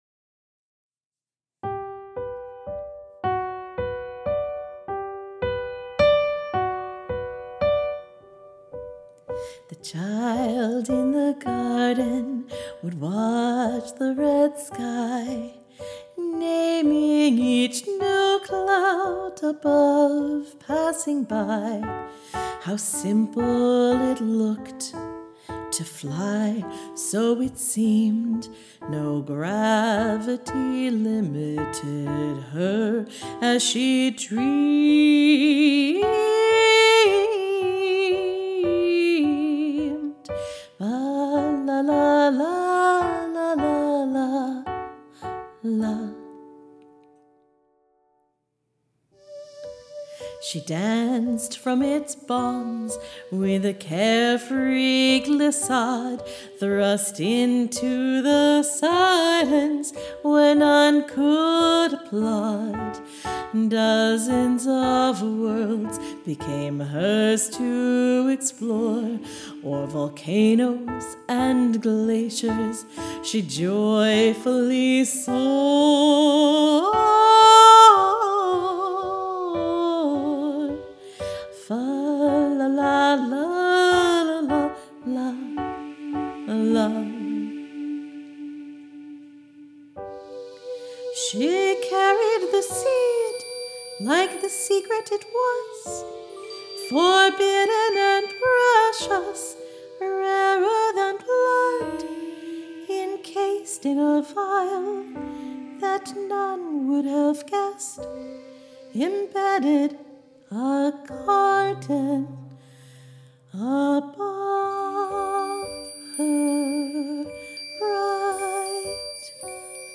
(And another reason to use twinkling sounds) 😉